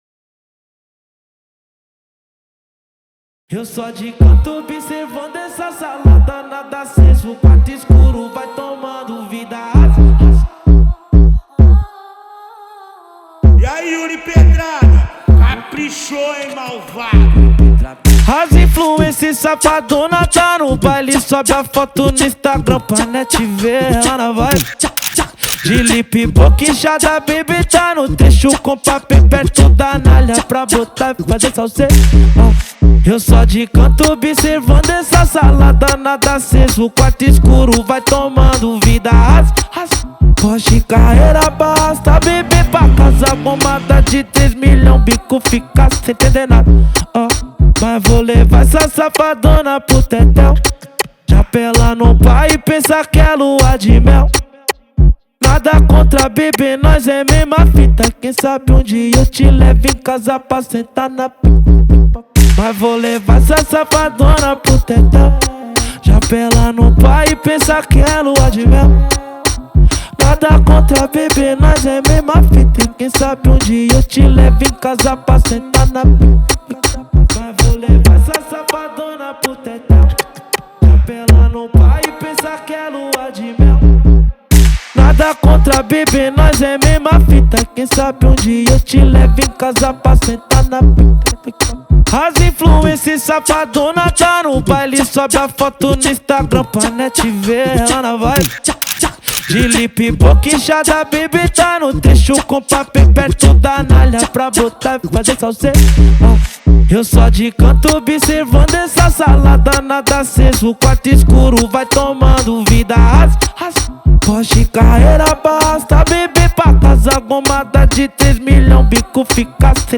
2025-02-08 01:25:40 Gênero: Funk Views